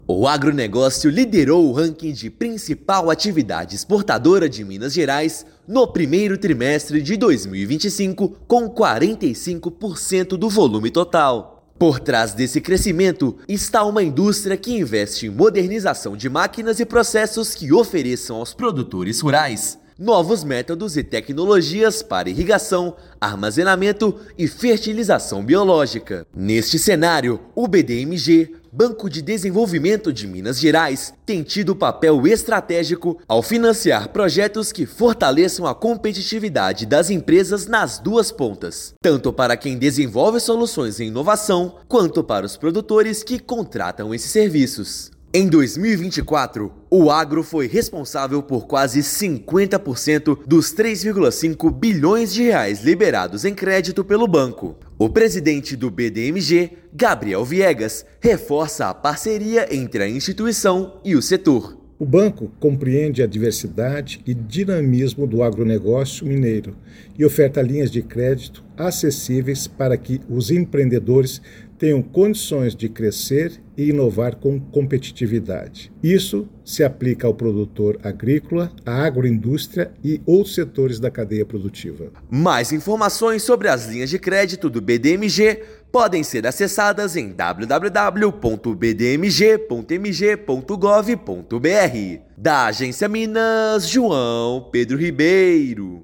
Com o financiamento do BDMG, empresas desenvolvem novas tecnologias de armazenamento e fertilização no setor que lidera exportações em Minas. Ouça matéria de rádio.